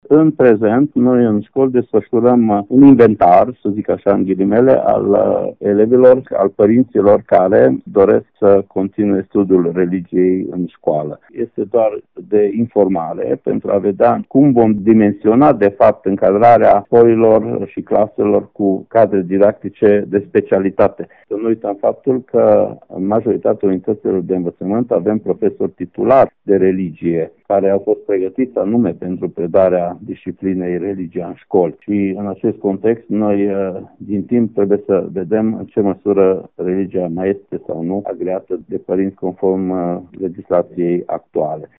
Inspectorul şcolar general Ştefan Someşan.